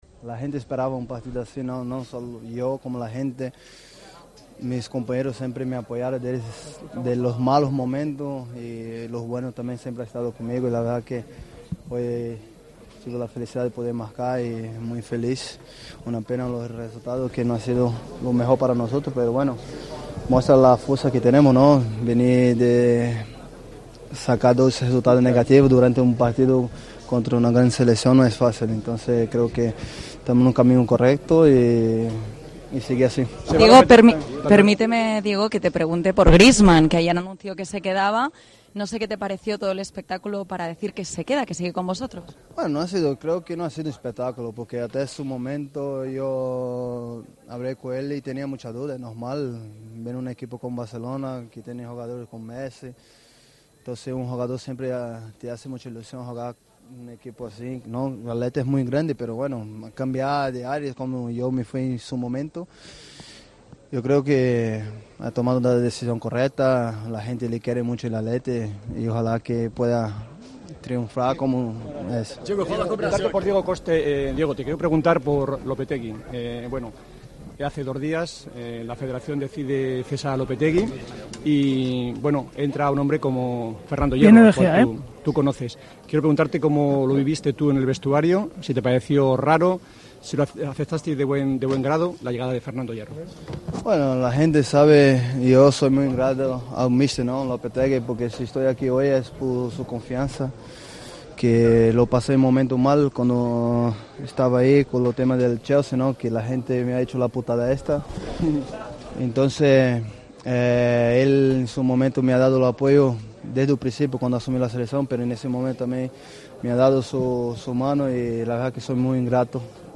El delantero de la selección española habló en zona mixta sobre el empate ante Portugal y su doblete en el primer partido del Mundial de Rusia.